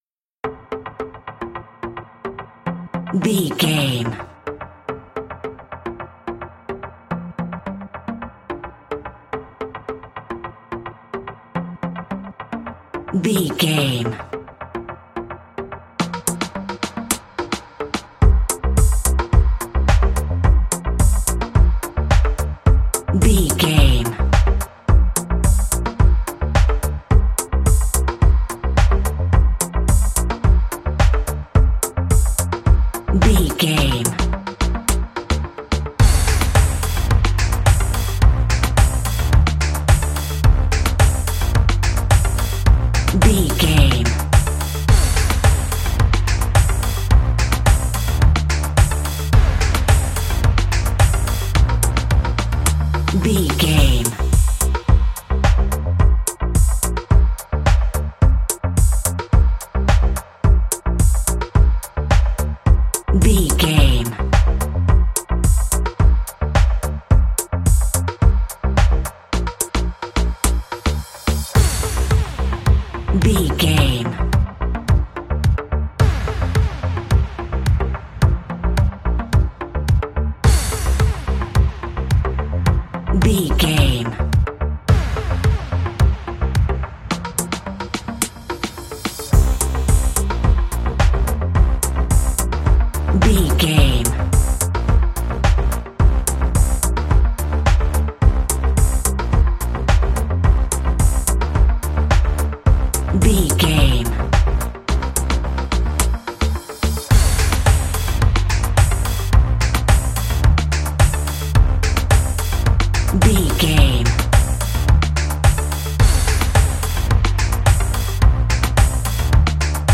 Classic reggae music with that skank bounce reggae feeling.
Ionian/Major
dub
laid back
chilled
off beat
drums
skank guitar
hammond organ
percussion
horns